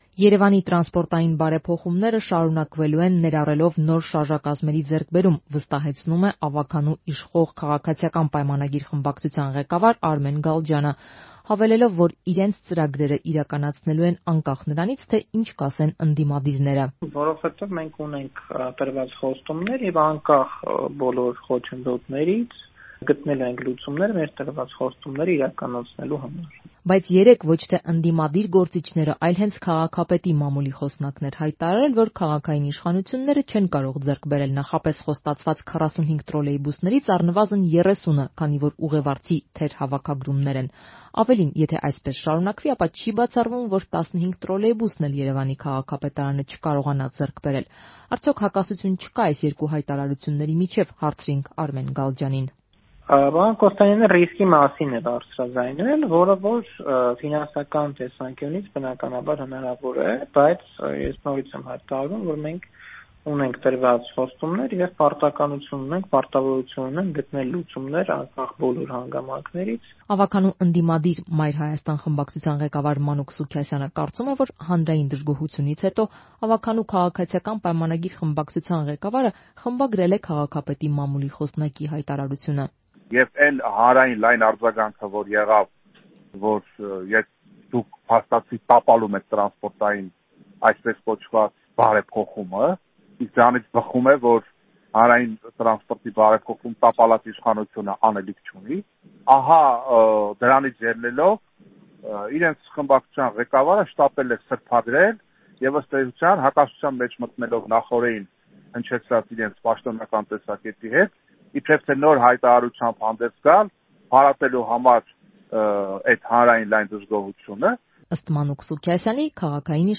Շարունակությունը՝ «Ազատության» ռադիոռեպորտաժում.